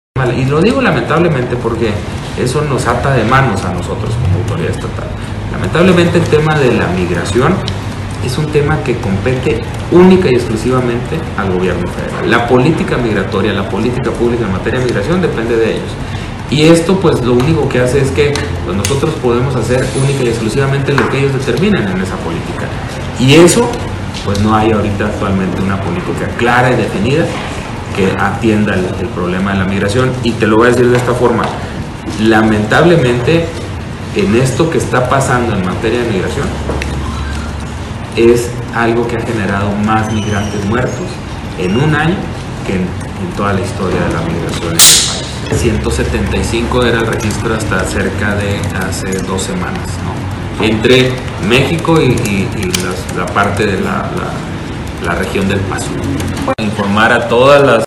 AUDIO: GILBERTO LOYA , SECRETARÍA DE SEGURIDAD PÚBLICA DEL ESTADO